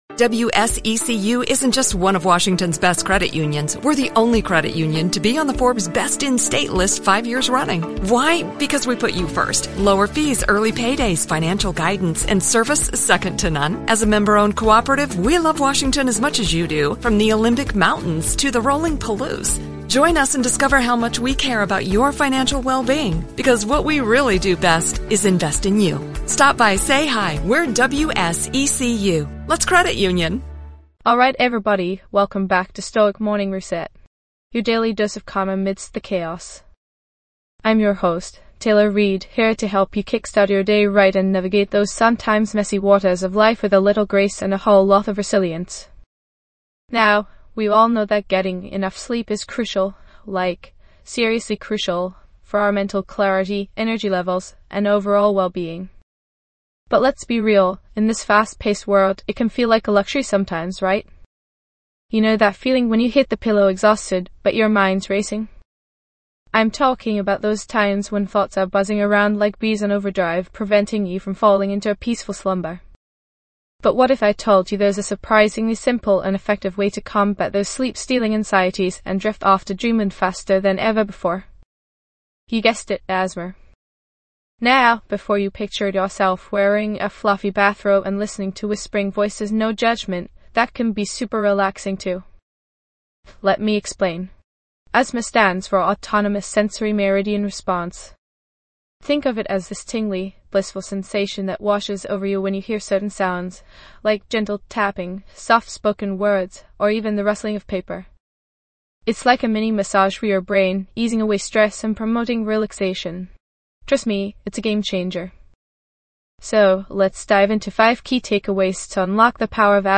Discover the art of falling asleep effortlessly with our special ASMR (Autonomous Sensory Meridian Response) episode.
This podcast is created with the help of advanced AI to deliver thoughtful affirmations and positive messages just for you.